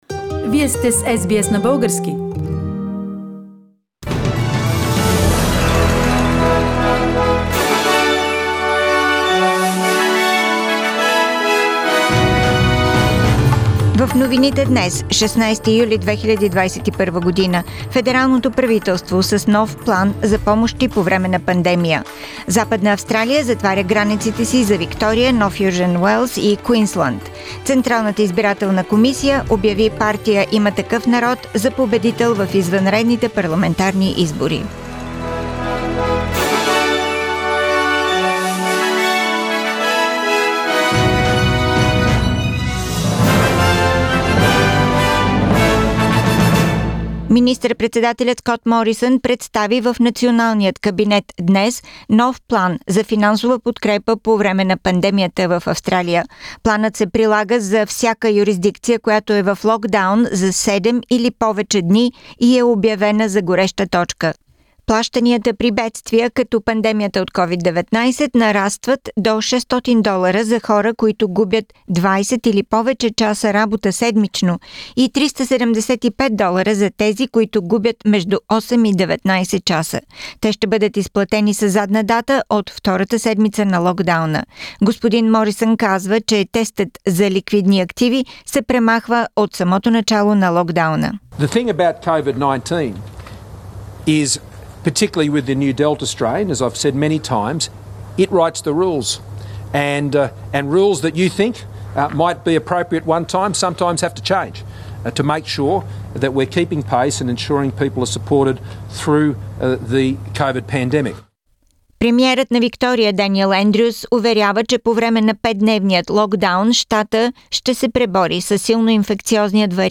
Weekly Bulgarian News – 16th July 2021